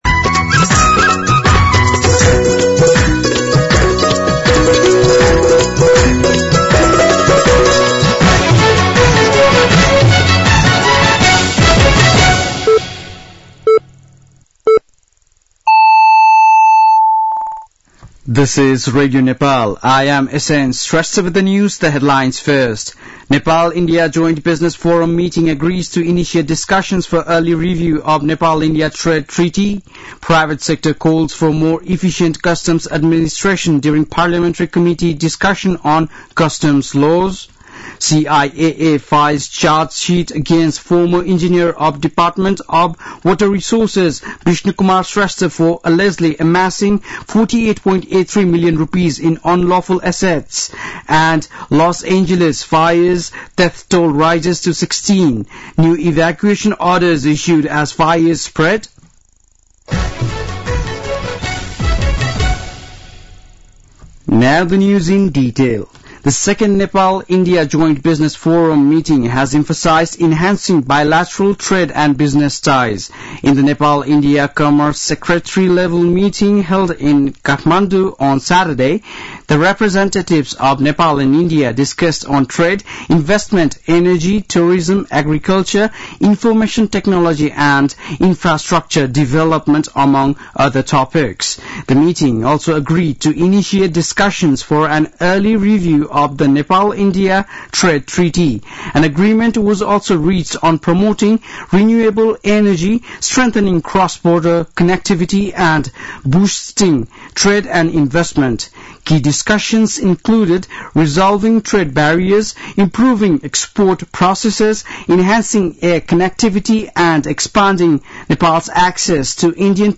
बेलुकी ८ बजेको अङ्ग्रेजी समाचार : २९ पुष , २०८१
8-PM-English-News.mp3